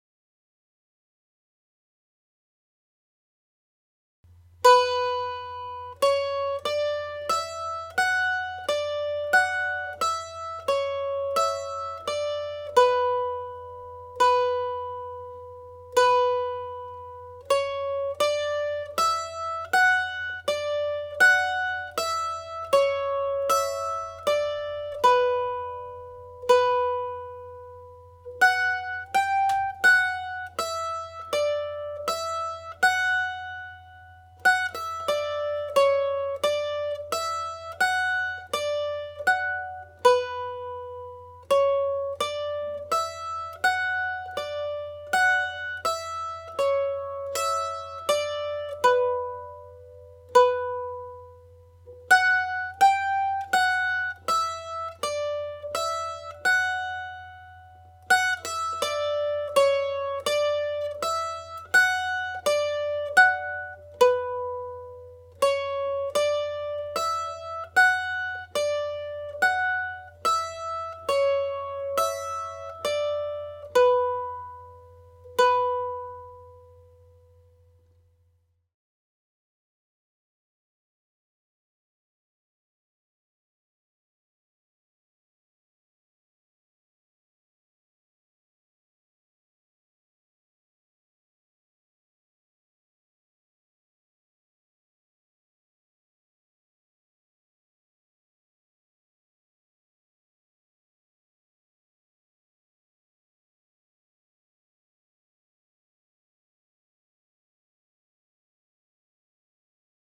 • banjo scale
Breton Dance Tune (A Minor)
played slowly